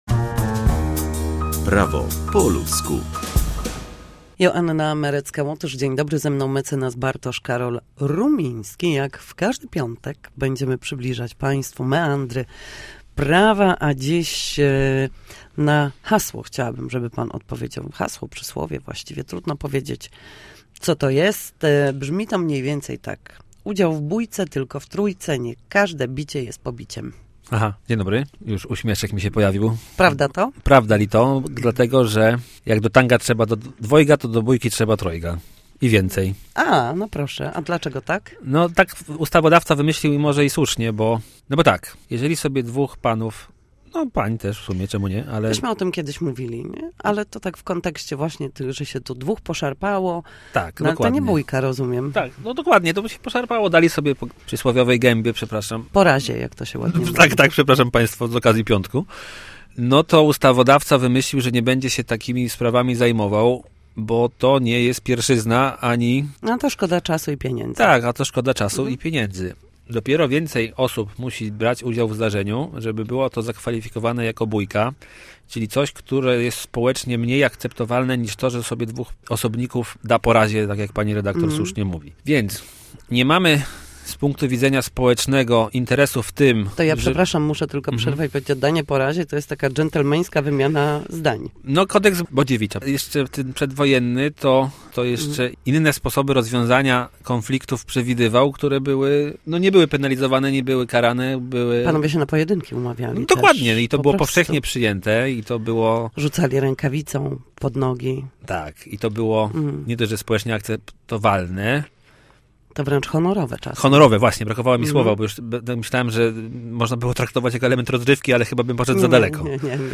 Nasi goście, prawnicy, odpowiadać będą na jedno pytanie dotyczące zachowania w sądzie czy podstawowych zagadnień prawniczych.